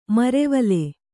♪ marevale